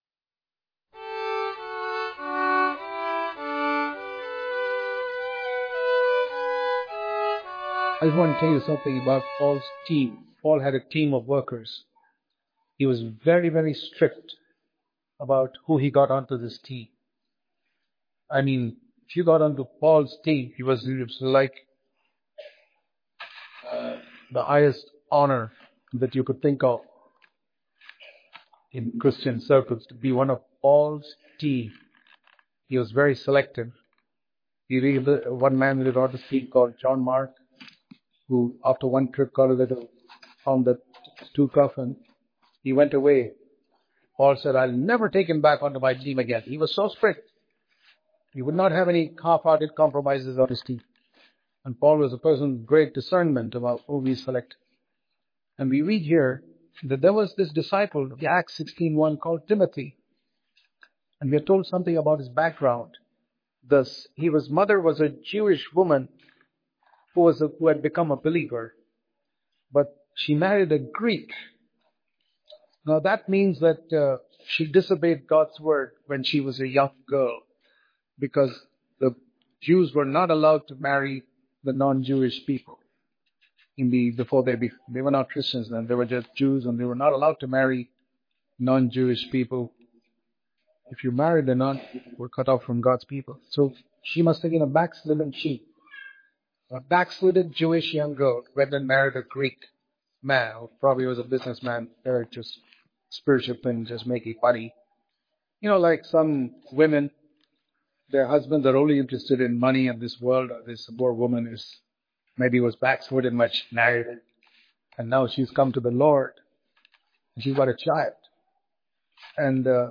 December 25 | Daily Devotion | Faith Of A Child Developed Through The Mother’s Faith Daily Devotion